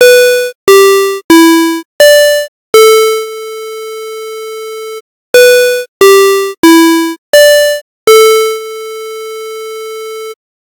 safe opening sound.
safe-sound.ogg